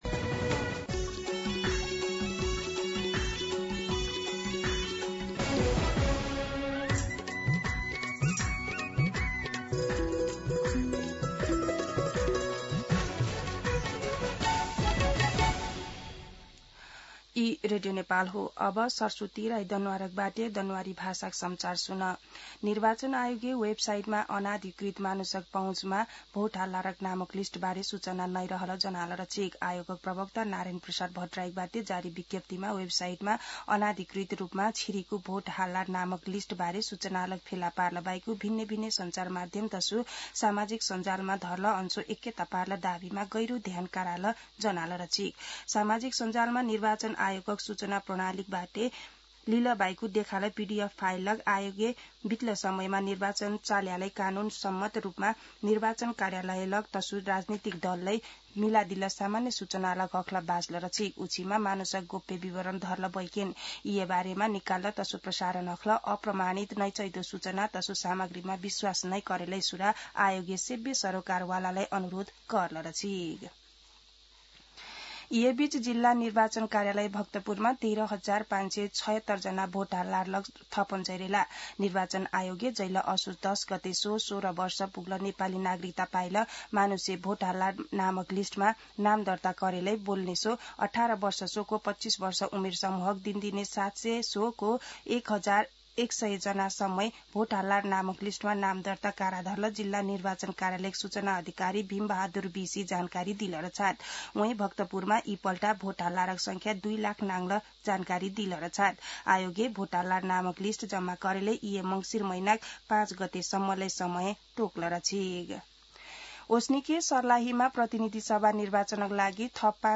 दनुवार भाषामा समाचार : २ मंसिर , २०८२
Danuwar-News-8-2.mp3